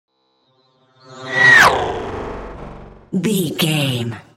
Sci fi vehicle whoosh
Sound Effects
Atonal
dark
futuristic
intense